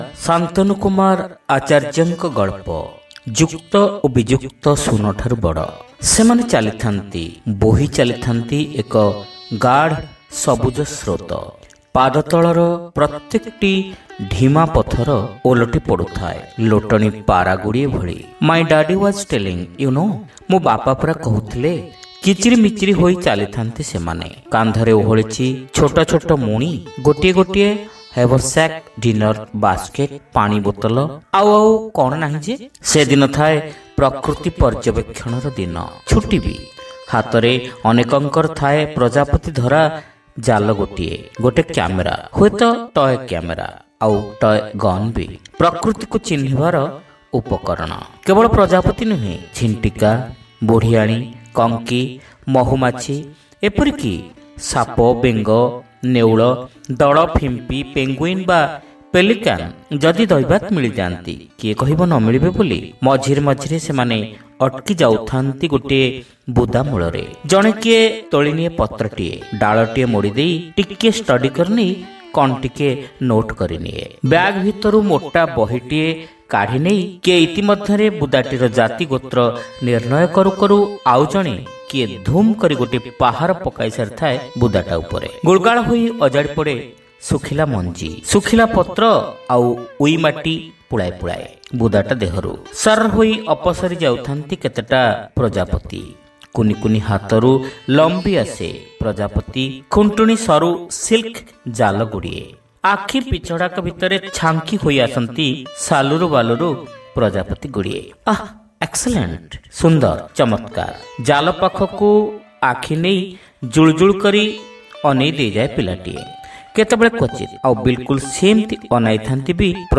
Audio Story : Jukta O Bijukta Suna Tharu Bada